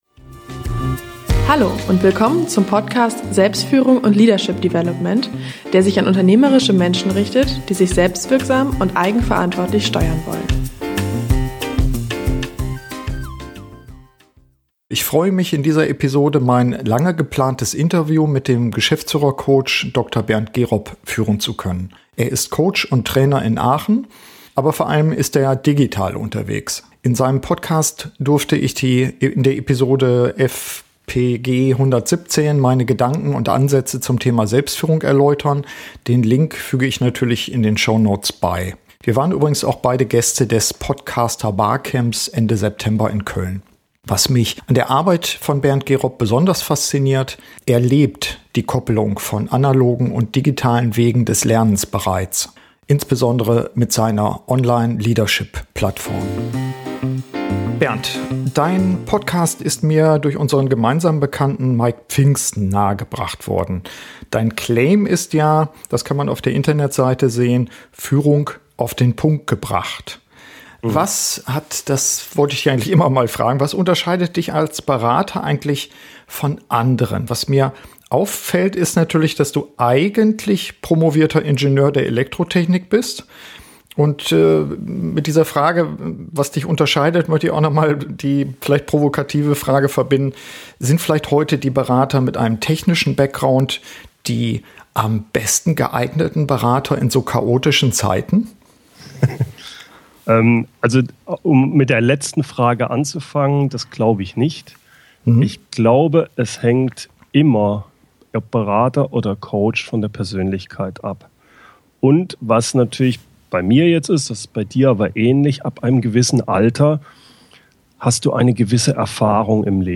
In unserem Gespräch zeigen wir auf, wie digitale und analoge Lernmethoden passgenau ineinander greifen.